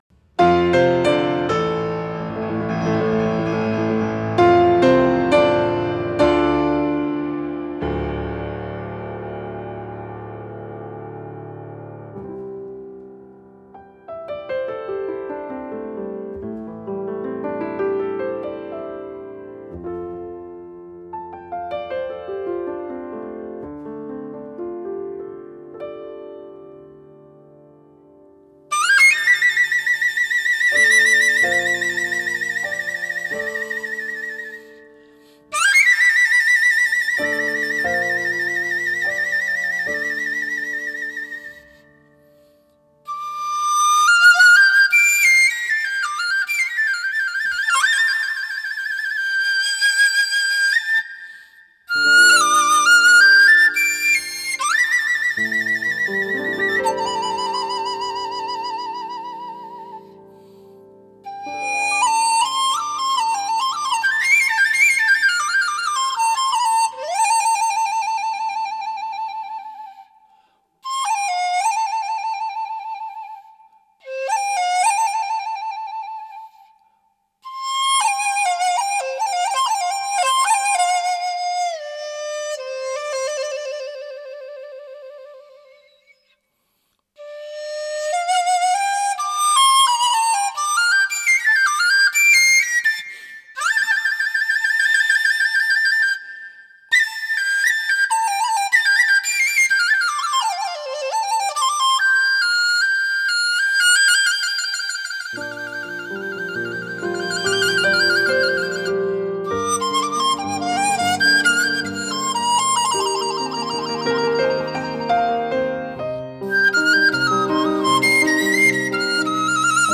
笛子与钢琴的对话
音乐类型: 民乐